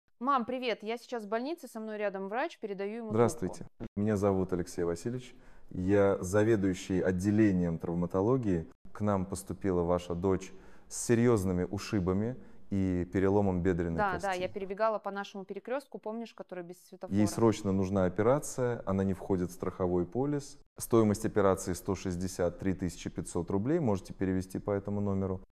5. Неестественное наложение голосов в диалоге
Если в диалоге реплики перебивают друг друга или «наслаиваются», это точно признак того, что речь искусственно сгенерирована.